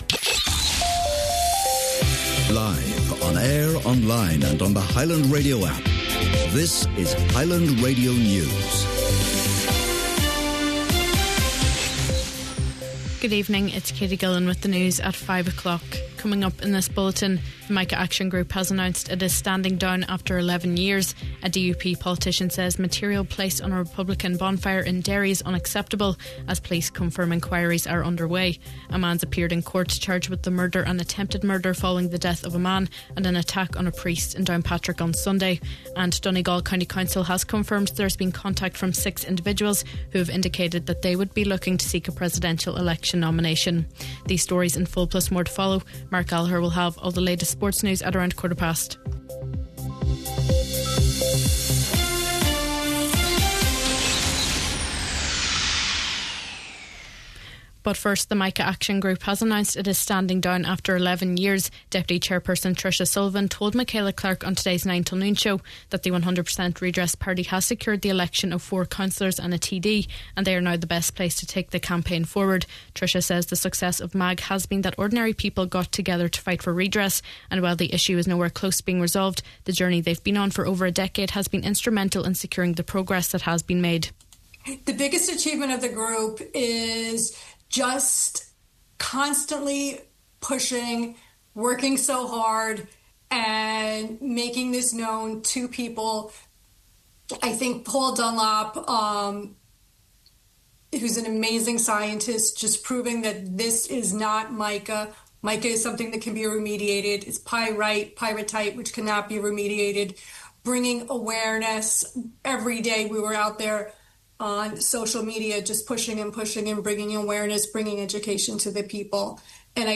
Main Evening News, Sport and Obituary Notices – Wednesday August 13th